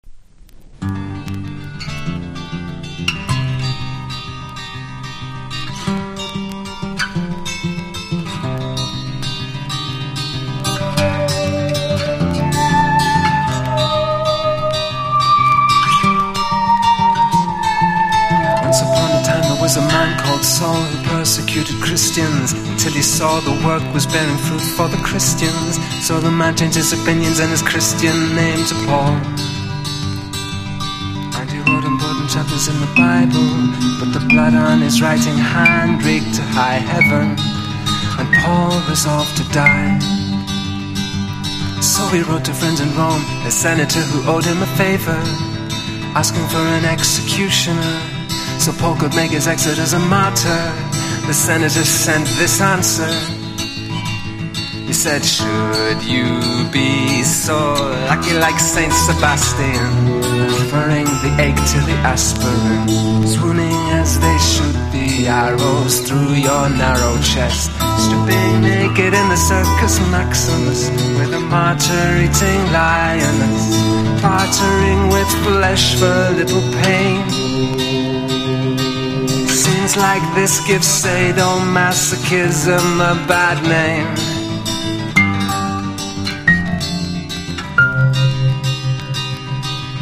1. 90'S ROCK >
NEO ACOUSTIC / GUITAR POP